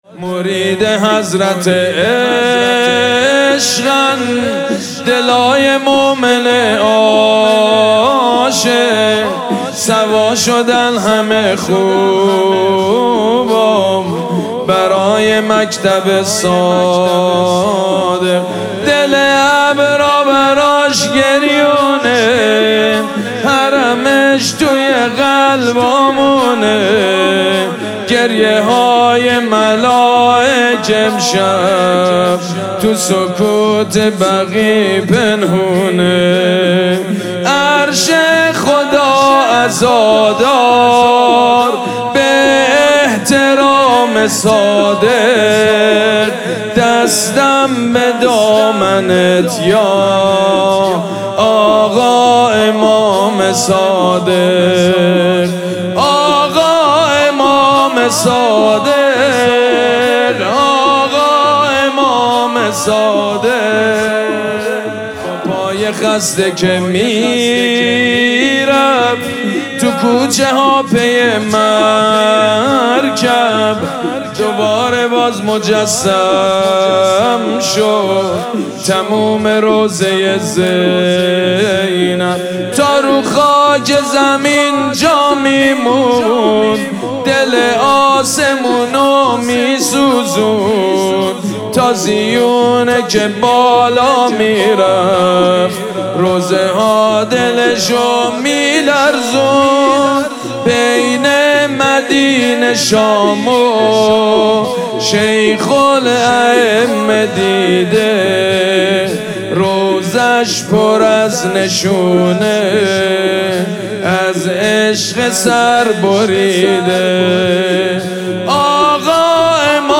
سید مجید بنی فاطمه |شهادت امام صادق (ع) 1400 | زینبیه اعظم دامغان | پلان 3